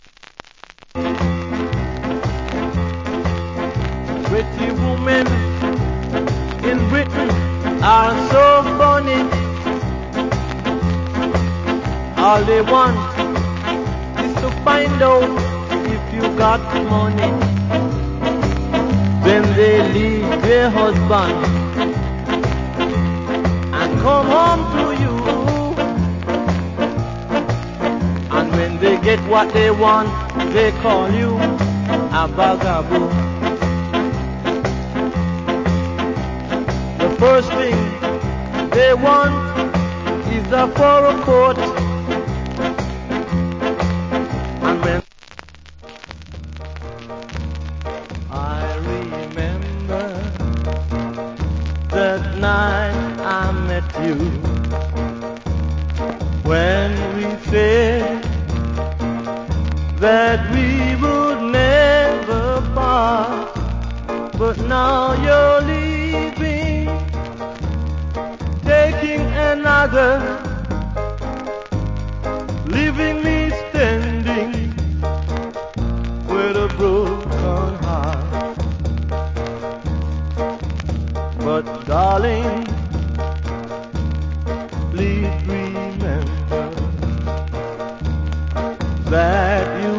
Nice Ska Vocal. / Good Vocal.